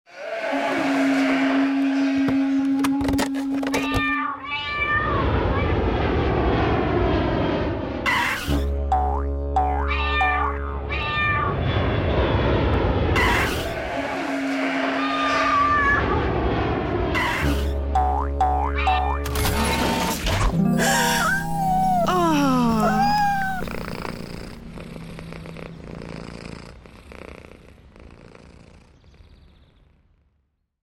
Es existiert meist unerkannt, man kann es nicht sehen, anfassen oder riechen - man kann es nur hören: Das Geräusch der Woche. Wir vertonen ein Ereignis der letzten sieben Tage.